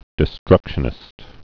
(dĭ-strŭkshə-nĭst)